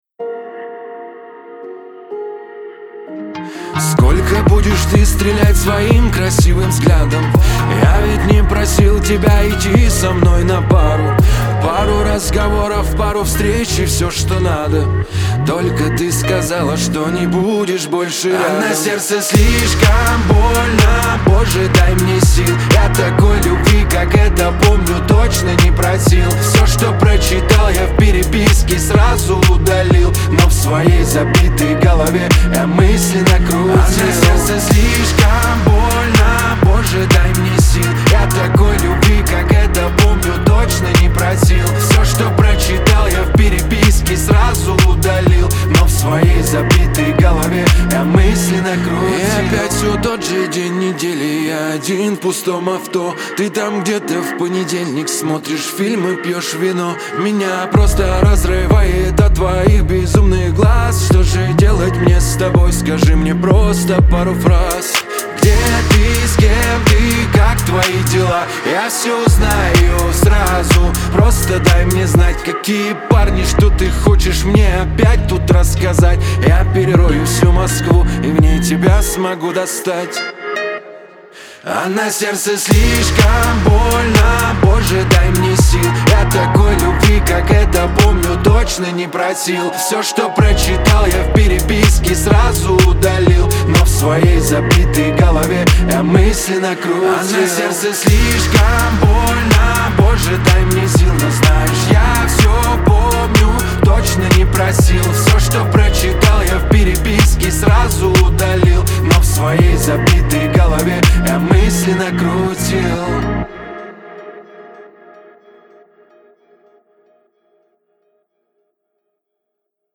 Лирика , ХАУС-РЭП